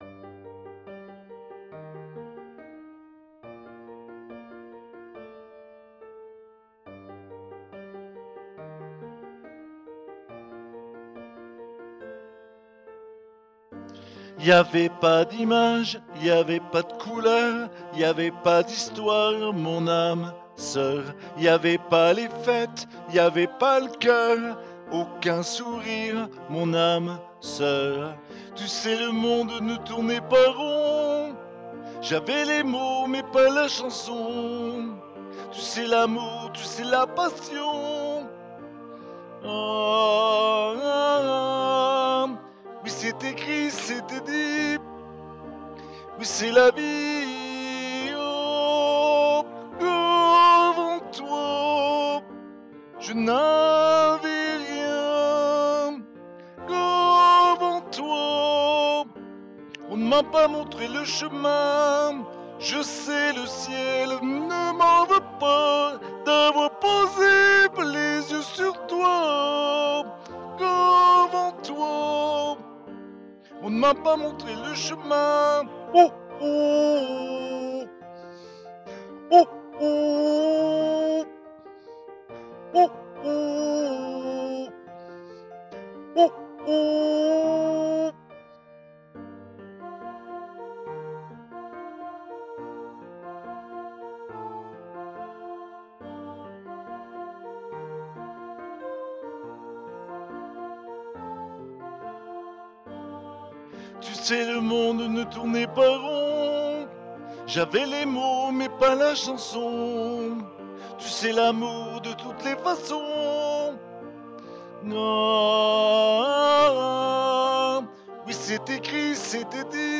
voix chantée